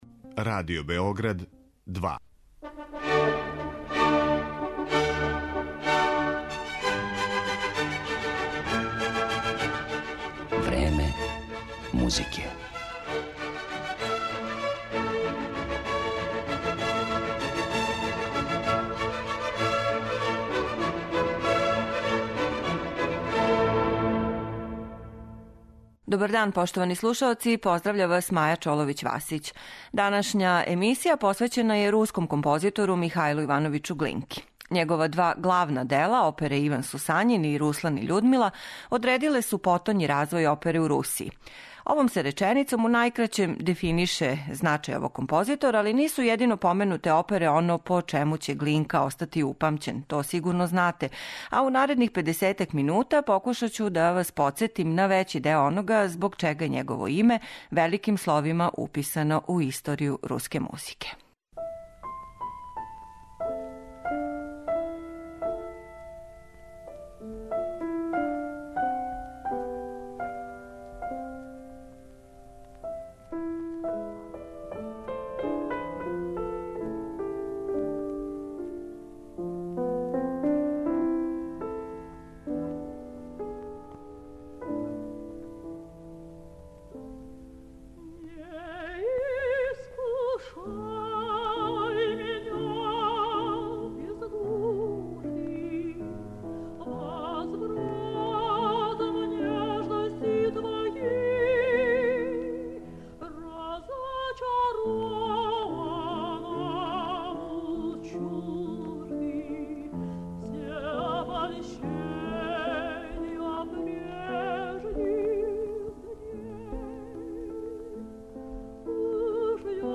Глинка је, такође, и творац низа веома успелих соло песама и камерних композиција, али је он стајао и на челу развоја руске симфонијске музике. Део тог опуса бићете у прилици да чујете у данашњој емисији